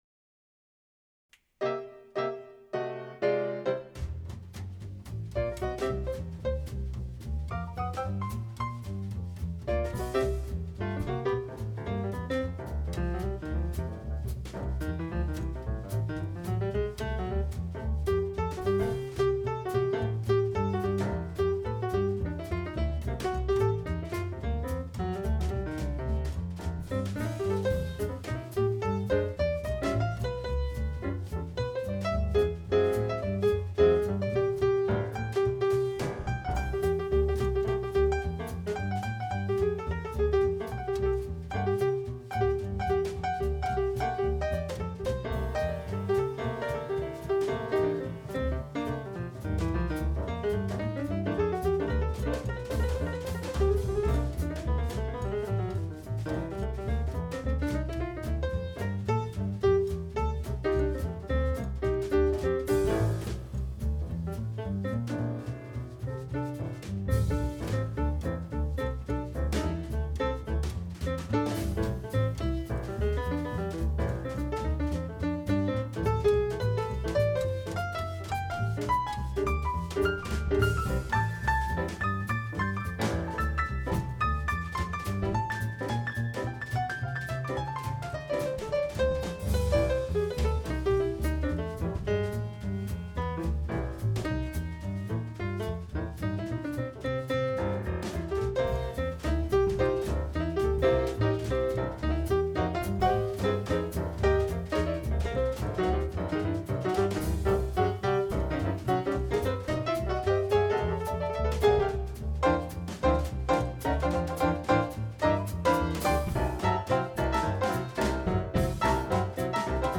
MVHS JAZZ ENSEMBLE PROJECTS BY YEAR
piano